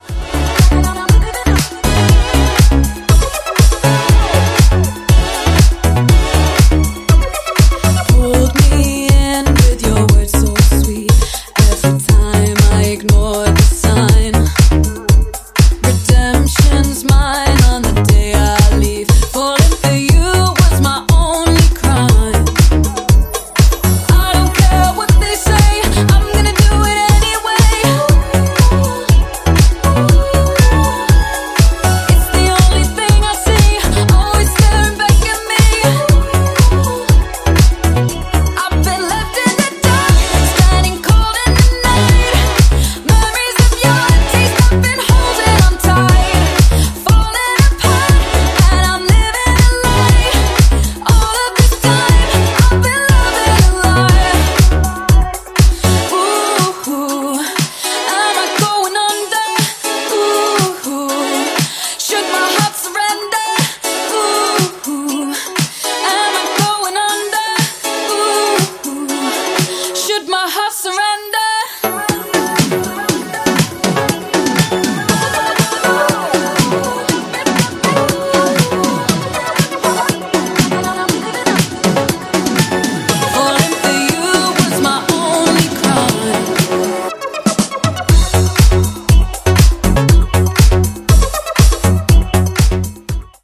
ジャンル(スタイル) NU DISCO / HOUSE